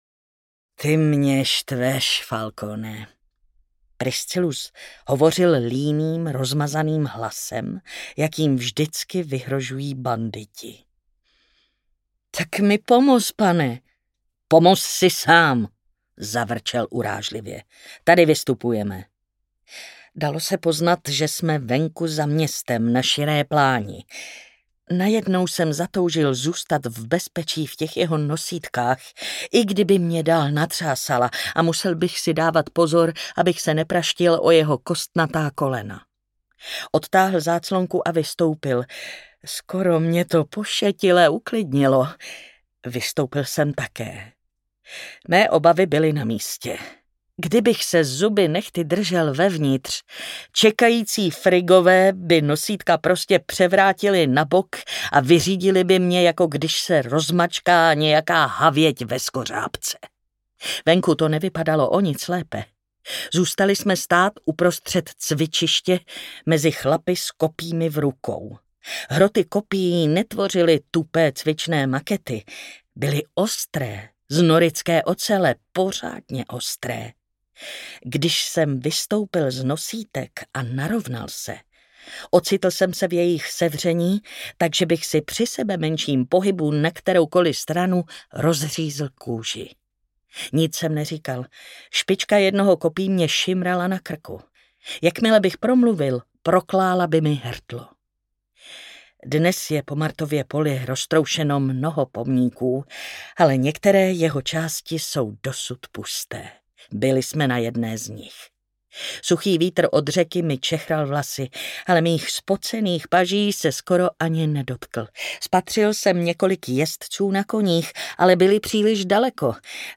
Měděná Venuše audiokniha
Ukázka z knihy